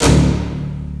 en ce moment je décortique un peu les sons d'instruments contenus dans FFXII pour me créer une banque de sons, mais je suis tombé sur des samples que j'arrive pas a identifier, ils sont souvents utilisés dans les musiques ou y'a de l'action (FFXII a des musiques dans un style symphonique/orchestral), voilà le meme instru sur 2 notes différentes:
c'est quoi exactement ? un pizzicato de contrebasse ?